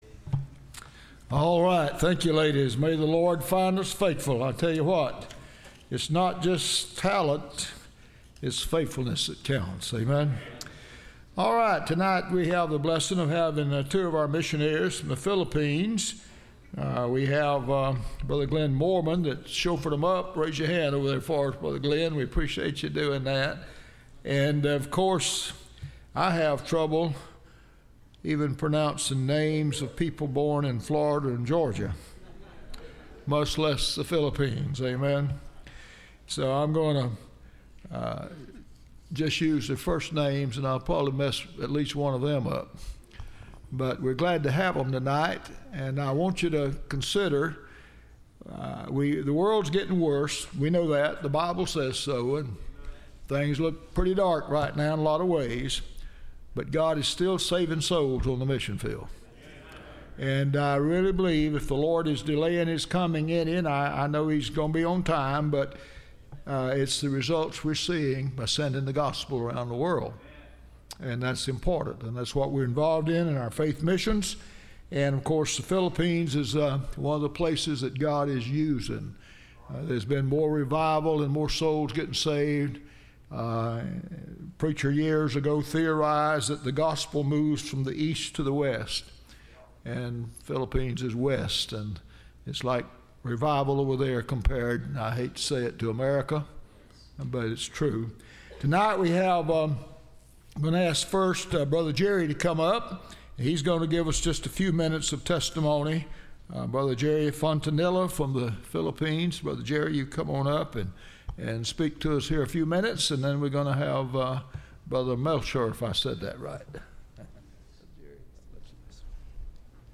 Service Type: Wednesday Missionary